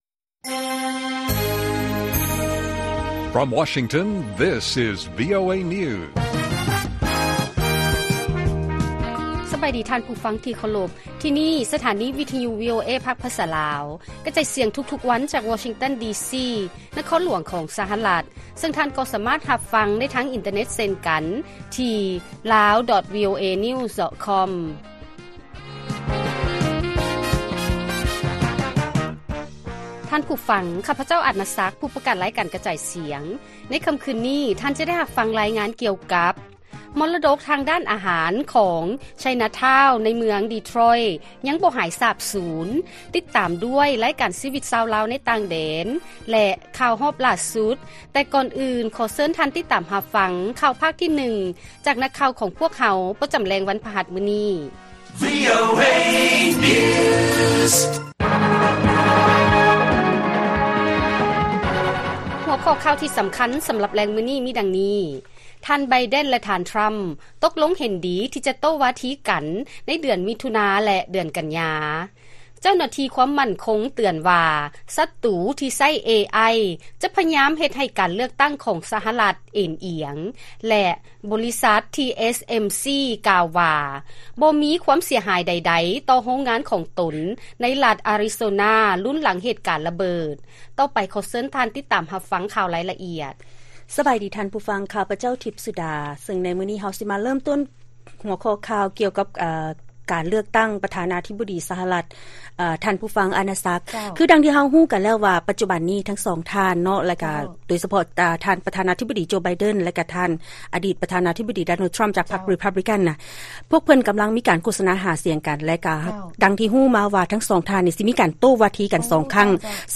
ລາຍການກະຈາຍສຽງຂອງວີໂອເອລາວ: ທ່ານ ໄບເດັນ ແລະ ທ່ານ ທຣໍາ ຕົກລົງເຫັນດີທີ່ຈະໂຕ້ວາທີກັນ ໃນເດືອນມິຖຸນາ ແລະ ເດືອນກັນຍາ